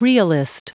Prononciation du mot realist en anglais (fichier audio)